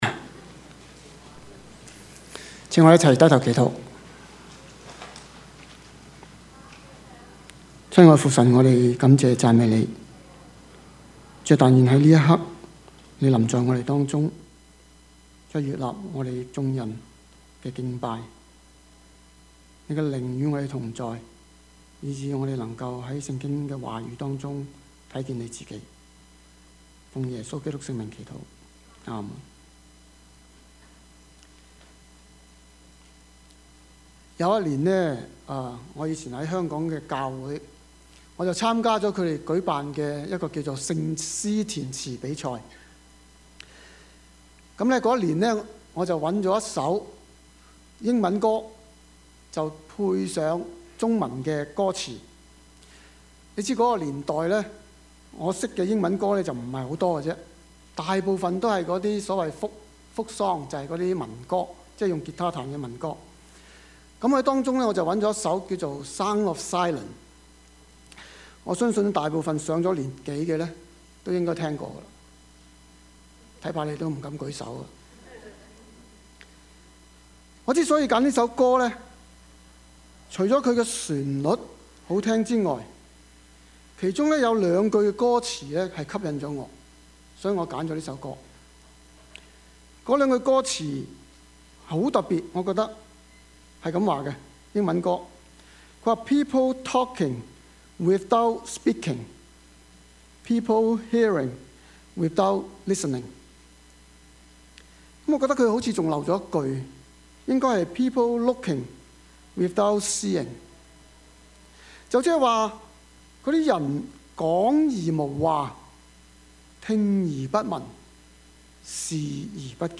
Service Type: 主日崇拜
Topics: 主日證道 « 跨越籬笆 大的樣子 »